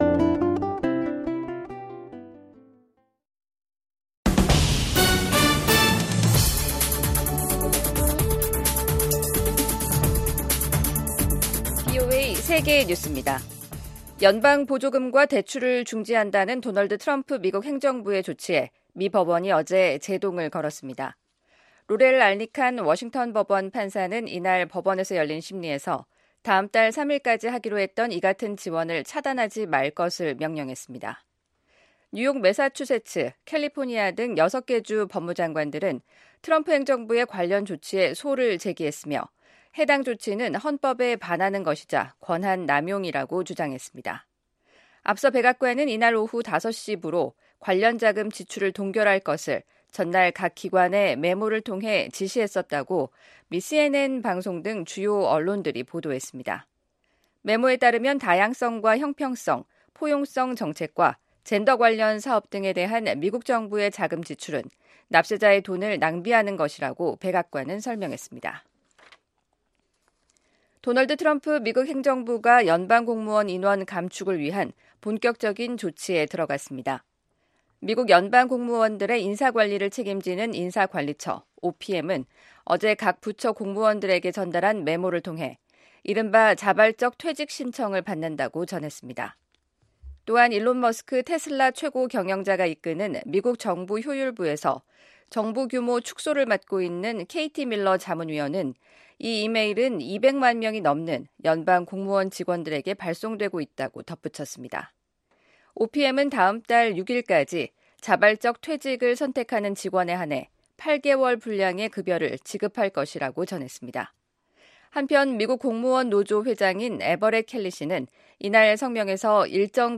VOA 한국어 간판 뉴스 프로그램 '뉴스 투데이', 2025년 1월 29일 2부 방송입니다. 미국 백악관은 ‘북한의 완전한 비핵화’가 여전히 트럼프 행정부의 목표라고 확인했습니다. 도널드 트럼프 대통령이 미국의 차세대 미사일 방어 시스템 개발을 촉구하는 행정명령에 서명했습니다. 김정은 북한 국무위원장은 핵 대응 태세를 무한히 강화한다는 입장을 거듭 밝혔습니다.